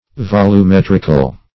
Volumetrical \Vol`u*met"ric*al\